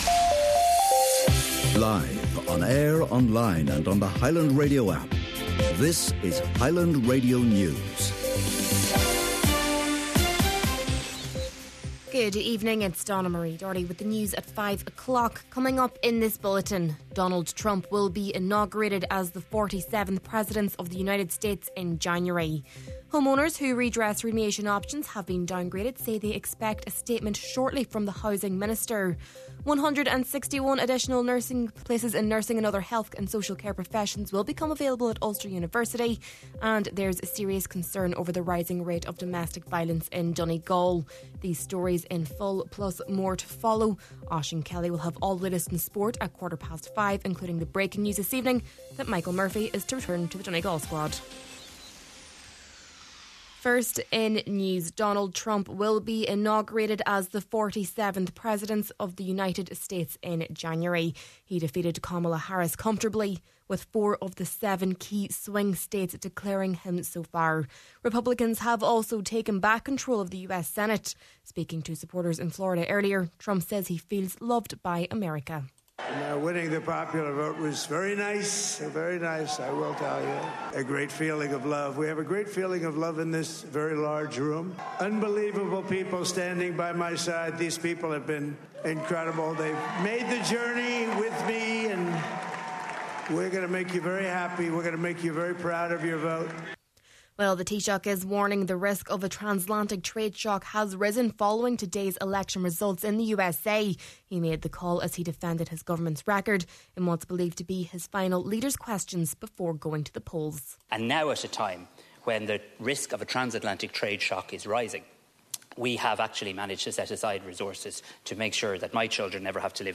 Main Evening News, Sport and Obituaries – Wenesday, November 6th: